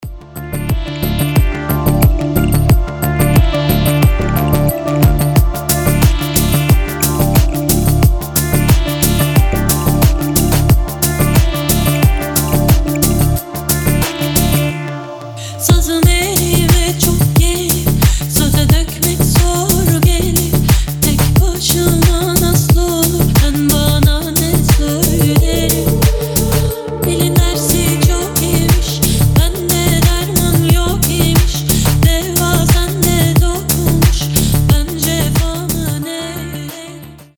• Качество: 320, Stereo
deep house
атмосферные
релакс
Downtempo
восточные
расслабляющие
этнические
Атмосферная турецкая музыка в стиле organic house